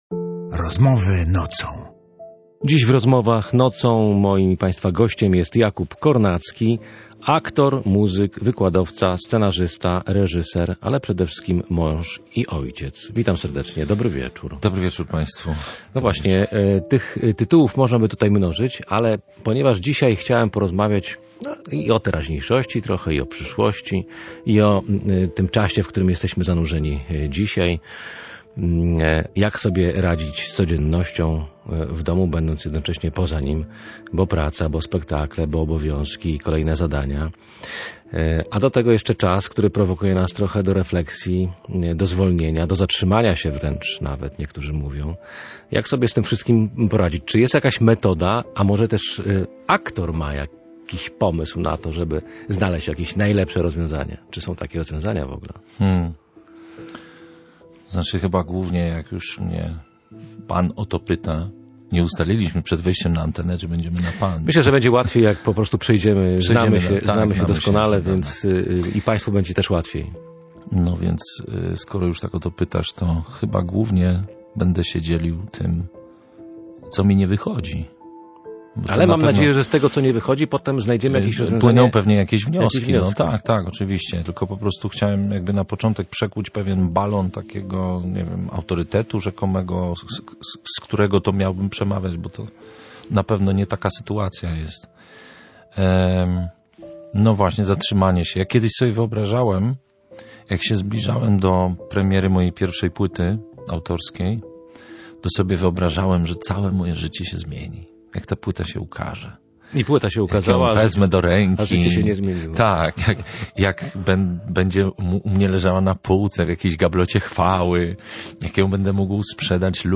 Zapracowani, ciągle zajęci, bez czasu na odpoczynek – a tu propozycja, by zwolnić, aż do zatrzymania się. W audycji „Rozmowy Nocą” rozmawialiśmy o znaczeniu i mądrym zagospodarowaniu czasu Wielkiego Postu.